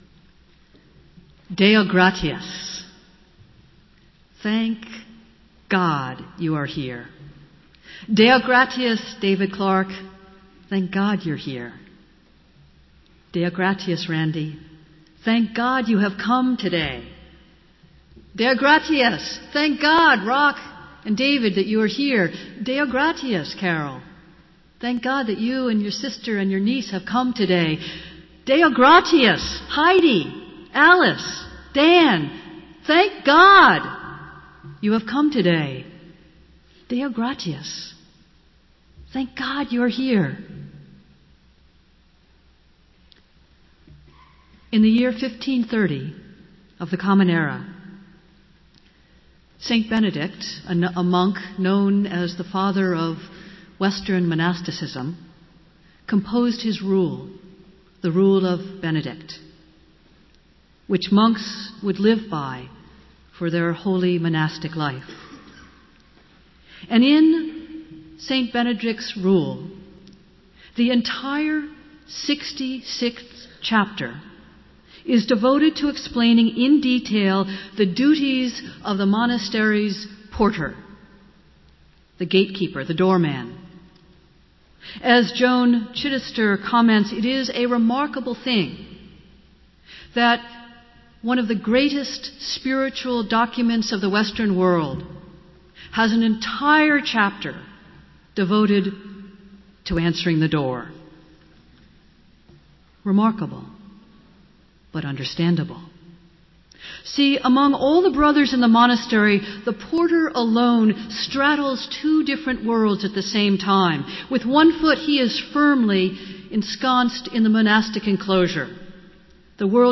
Festival Worship - All Saints' Sunday | Old South Church in Boston, MA
Festival Worship - All Saints' Sunday